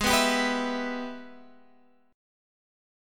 G#mM7#5 chord